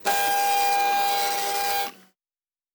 pgs/Assets/Audio/Sci-Fi Sounds/Mechanical/Servo Small 10_2.wav at master
Servo Small 10_2.wav